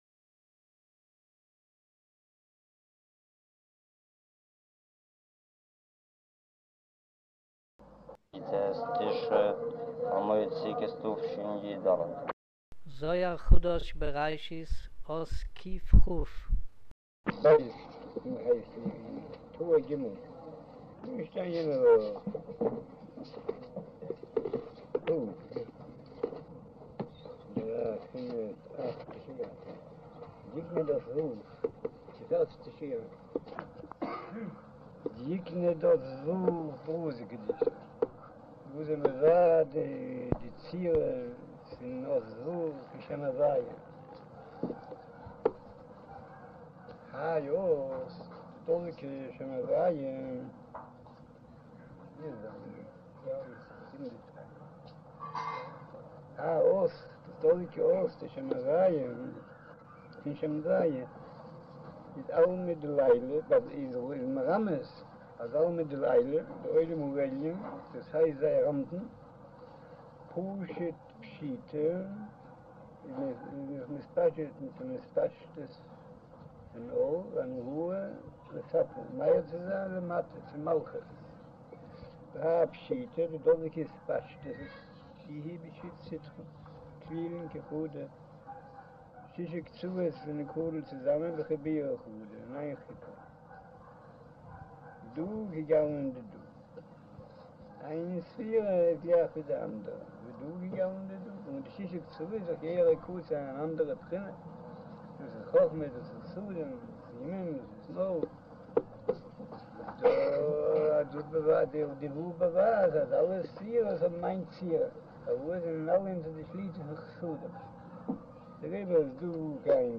אודיו - שיעור מבעל הסולם זהר חדש בראשית אות קכ' - קלא'